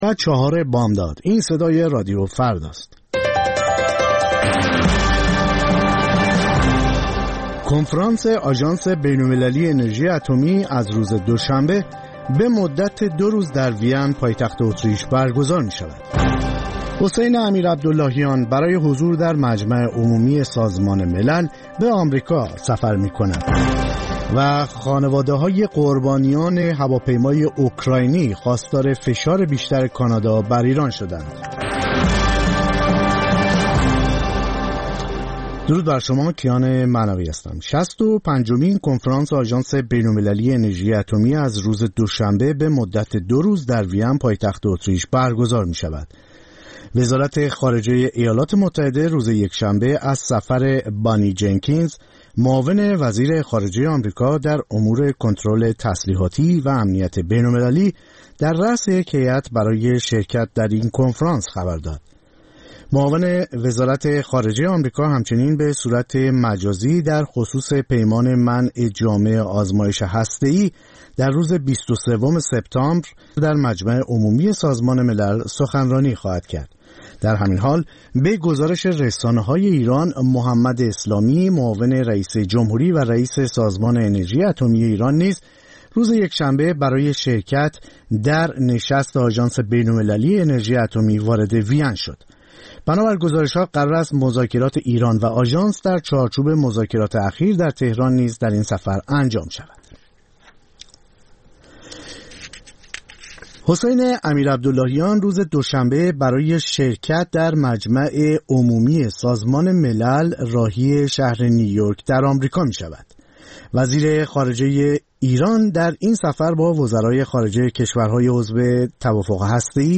سرخط خبرها ۴:۰۰